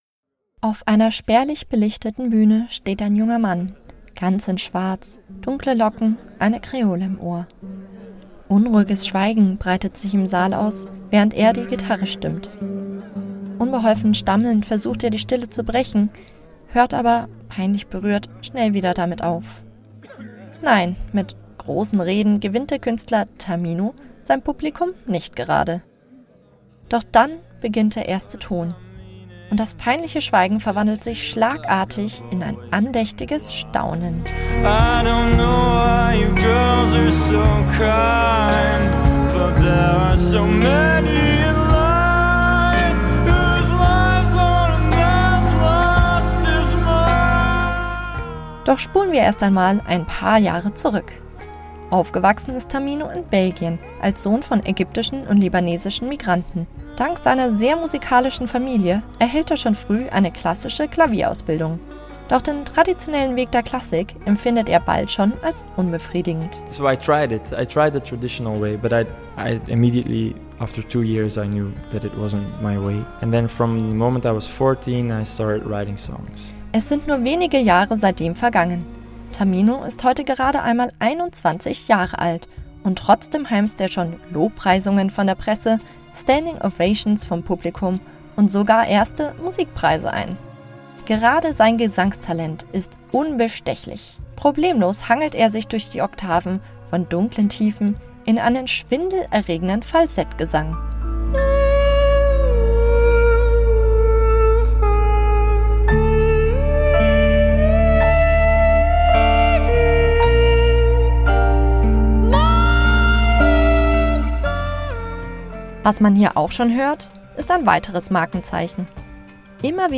Im Interview mit dem belgischen Singer-Songwriter Tamino | © couchFM